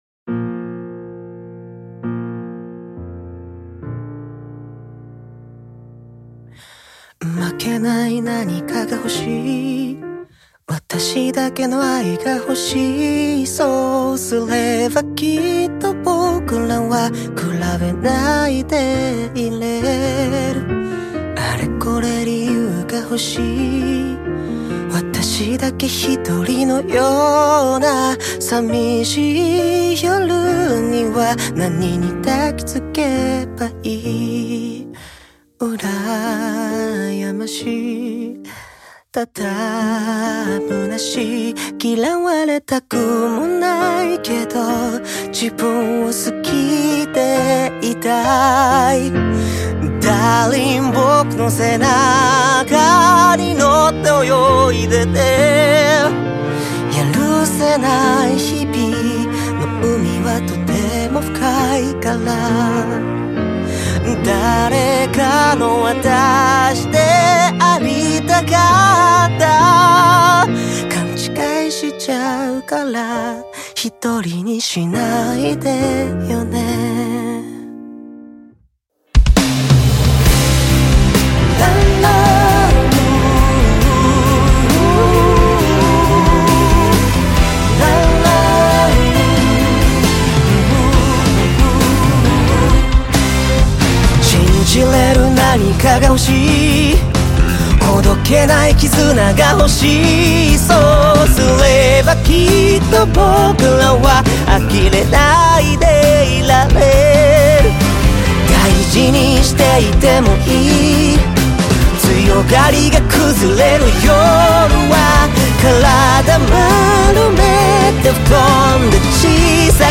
Label Pop